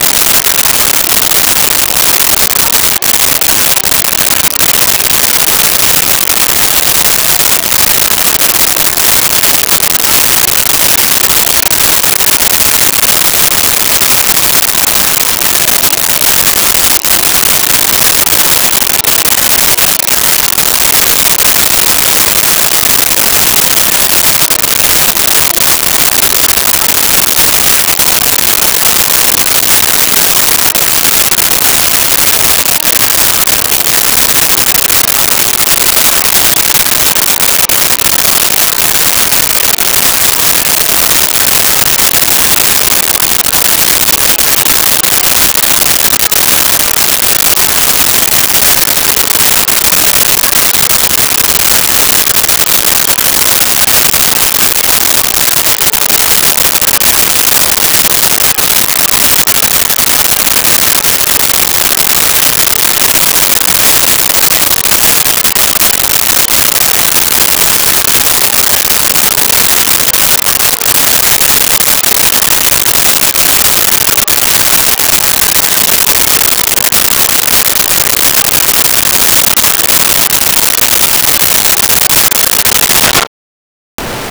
Hi Pitch Crowd Walla 01
Hi Pitch Crowd Walla 01.wav